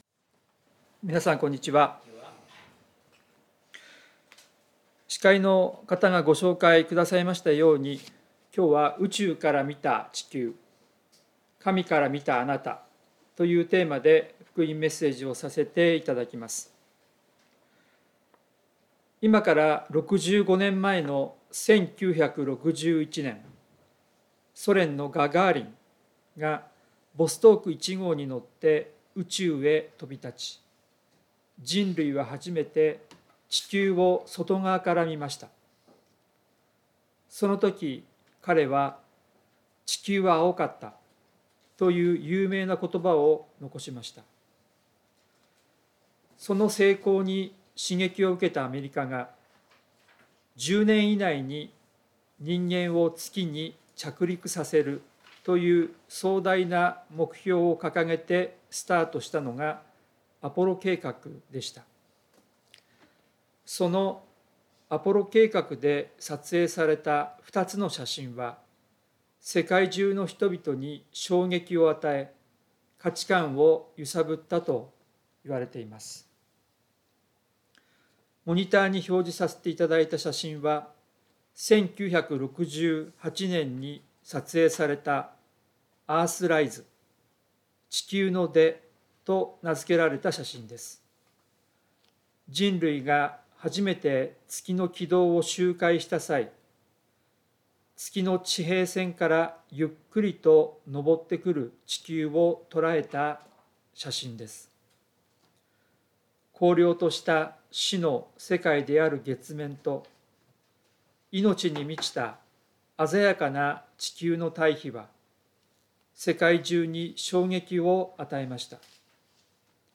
聖書メッセージ No.311